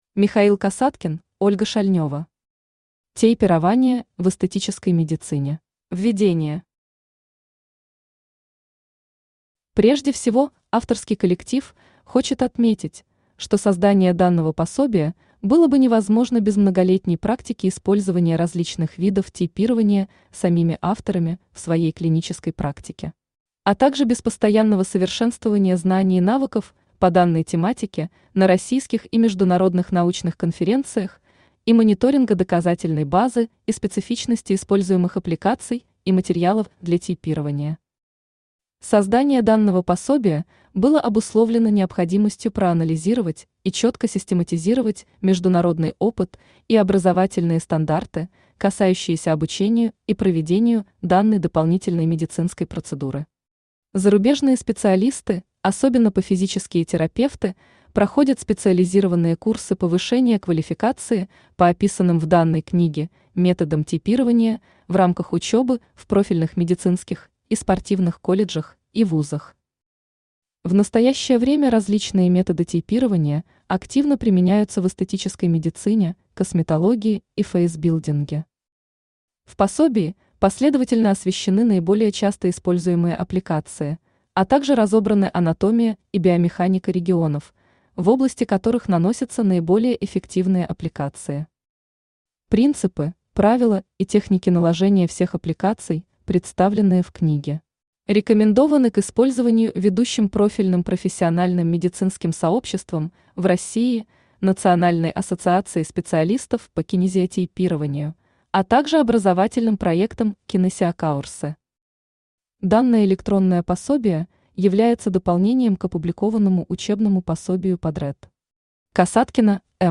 Аудиокнига Тейпирование в эстетической медицине | Библиотека аудиокниг
Aудиокнига Тейпирование в эстетической медицине Автор Михаил Сергеевич Касаткин Читает аудиокнигу Авточтец ЛитРес.